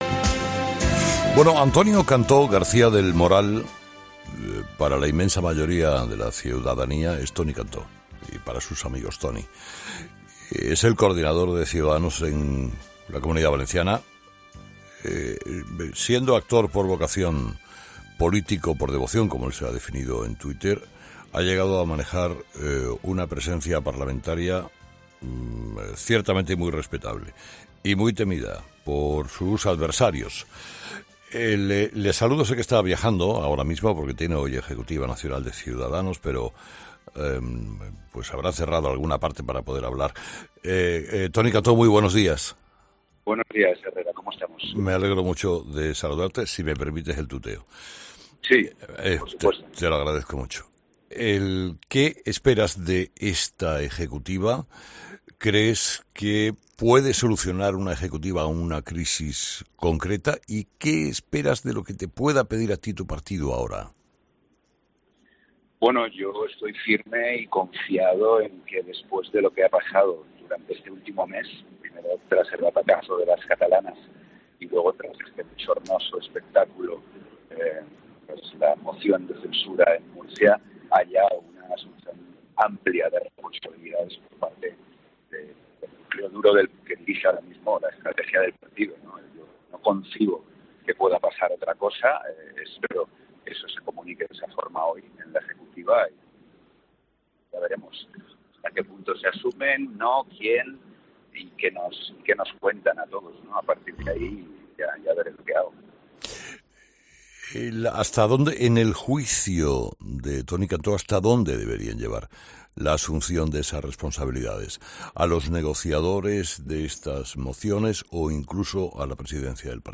Entrevista a Toni Cantó
Toni Cantó, coordinador autonómico de Ciudadanos en la Comunidad Valenciana, ha sido entrevistado este lunes en 'Herrera en COPE', donde ha dicho que confía en que después del fracaso en las elecciones catalanas y del "bochornoso espectáculo de la moción de censura en Murcia" se adopten responsabilidades por parte de quien dirija la estrategia del partido.